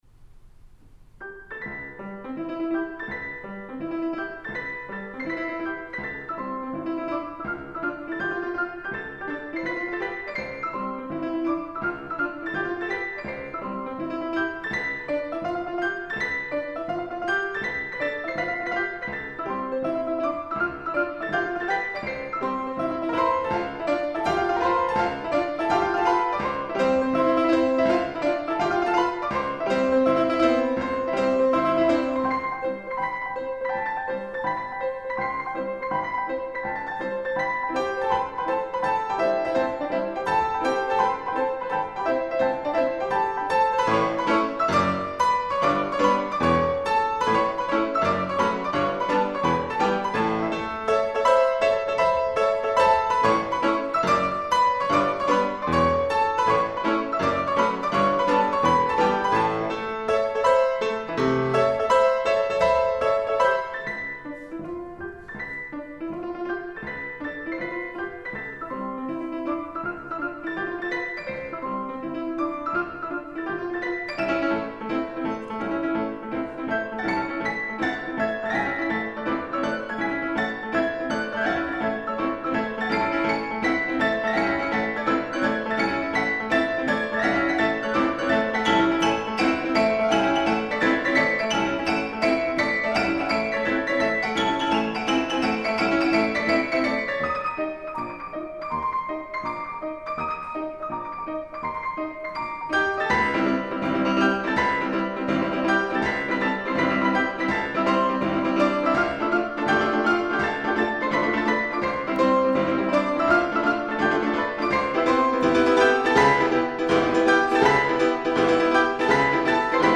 Two Pianos